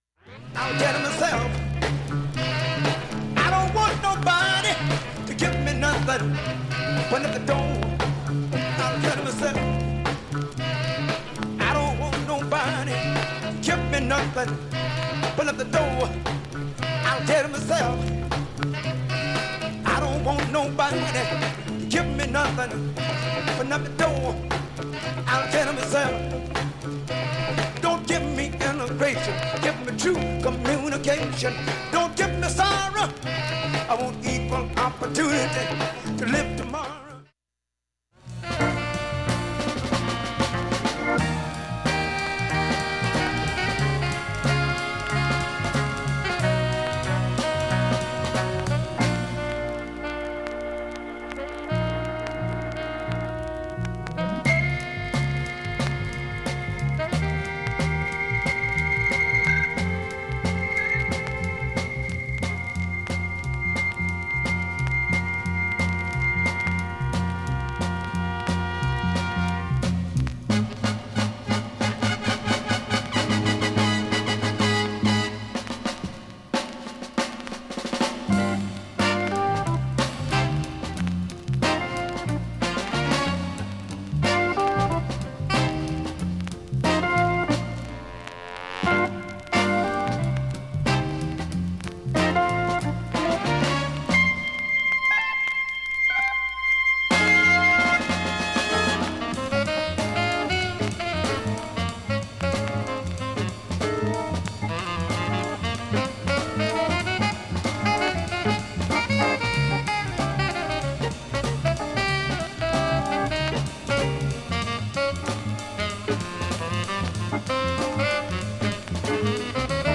AB面はプツも無く音質良好、
C面中盤まではかすかなチリ出ますが
D面はチリほとんどありません
95秒の間に周回プツ出ますがかすかです。
１分１０秒の間に周回プツ出ますがかすかです。
ギターをフィーチャーした